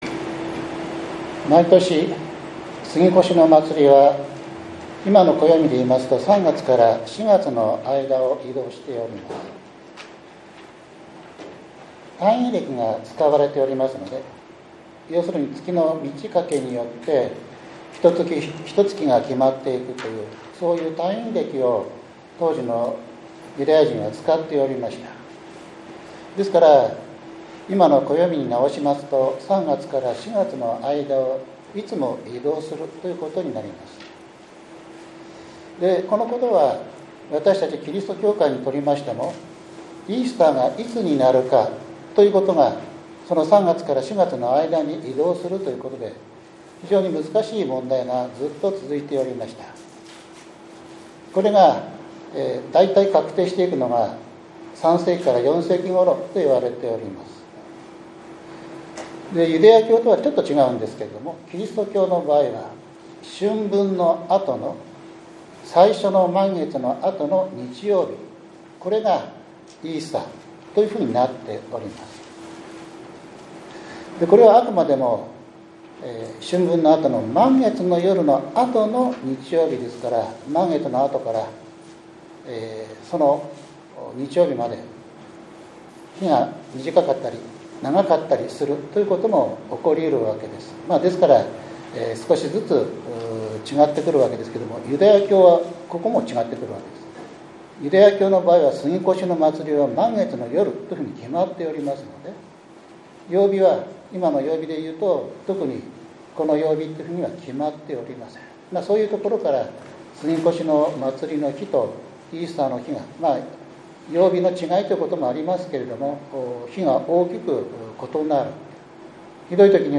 ２月２３日（日）主日礼拝